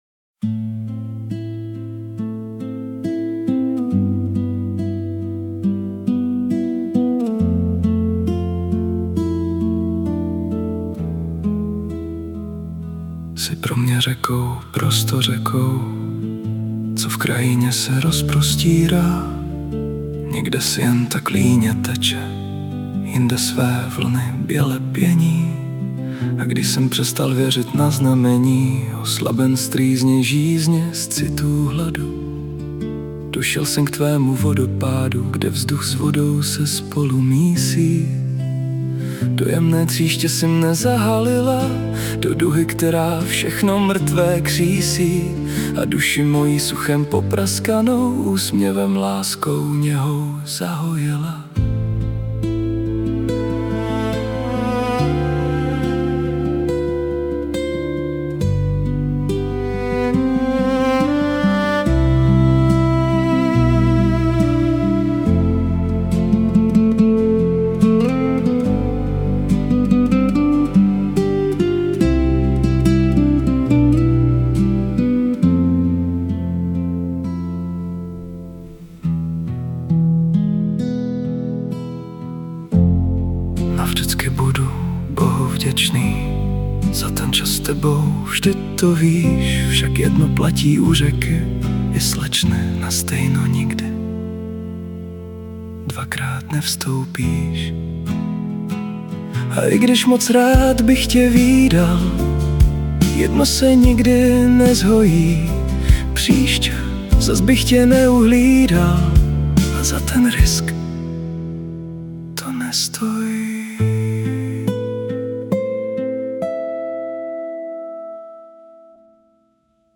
AI zhudebněno tady...
Snažím se, aby zhudebnění báseň podepřelo, ale nestrhlo na sebe moc pozornosti a nechalo slova vyznít.